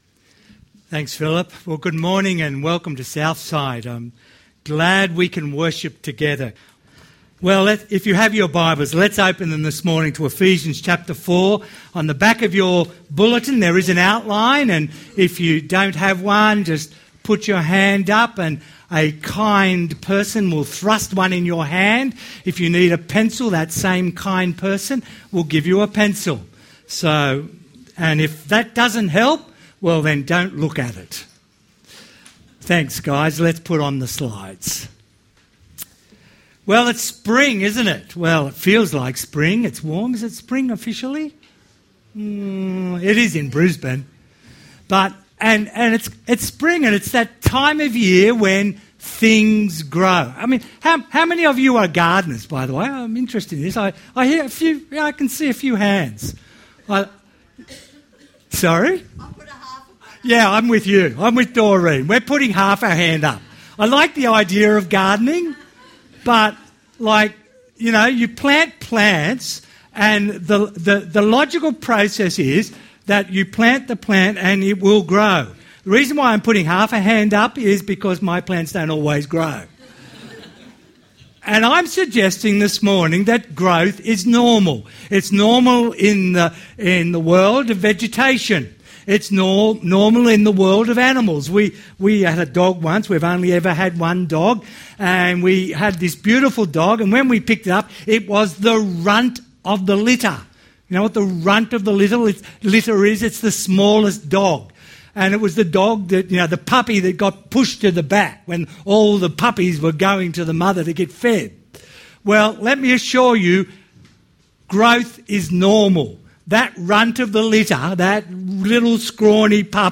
Ephesians 4:12-16 Listen to the sermon. Categories Sermon Tags ephesian